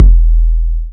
808-Kicks07.wav